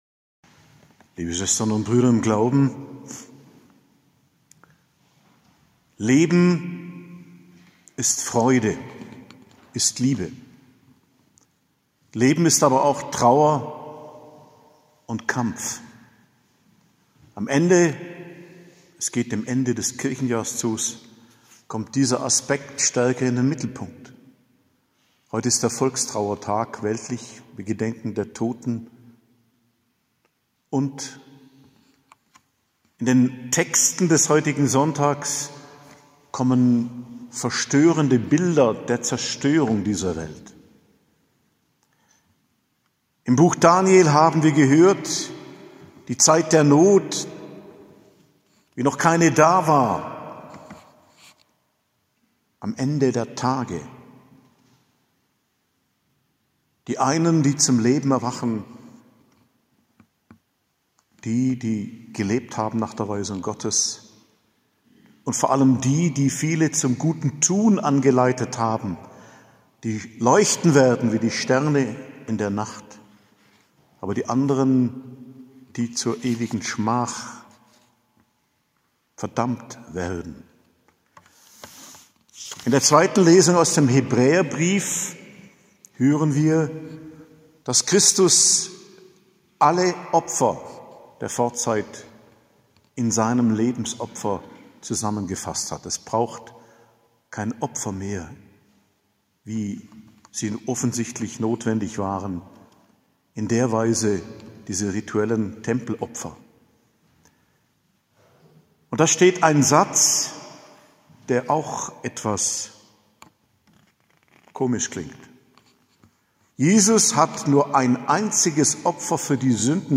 Predigt zum 33. Sonntag im Jahreskreis, 14.11.2021